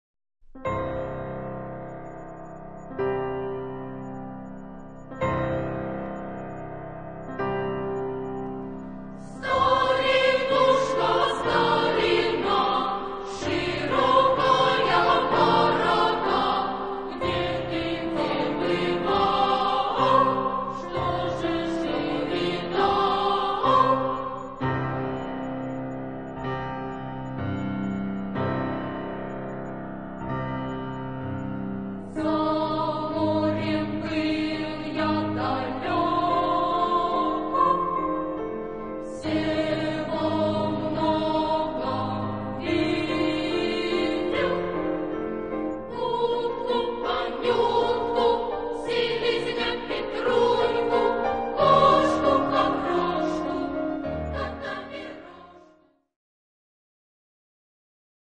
Secular ; Partsong
whimsical ; humorous ; playful
SA (2 children voices )
Piano (1)
Tonality: C major